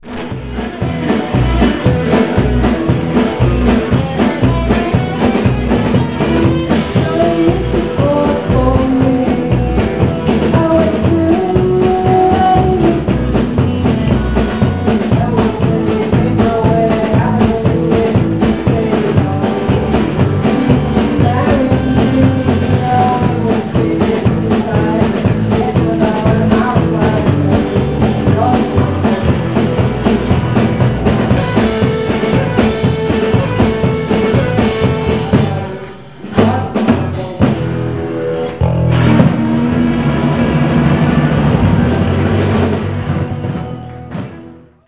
La Boite, Barcelona